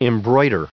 added pronounciation and merriam webster audio
1288_embroider.ogg